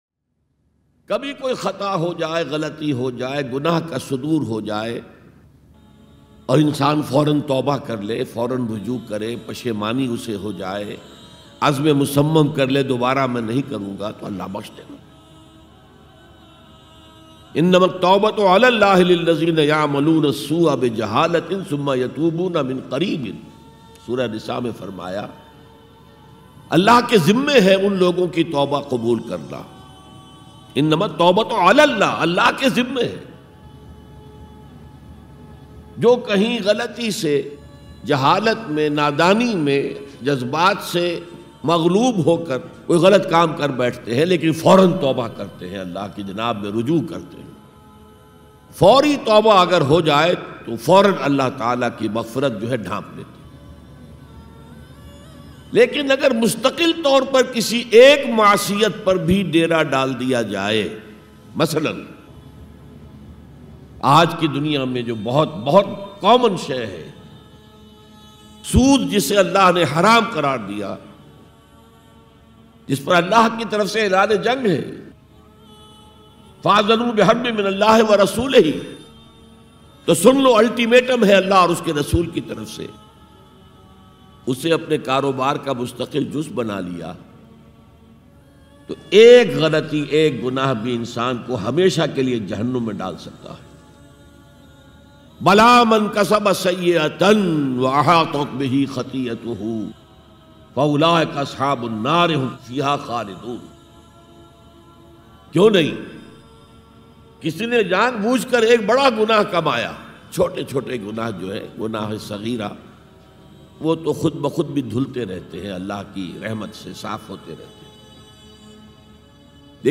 Tauba Karne Ka Tarika Bayan Dr israr Ahmed MP3 Download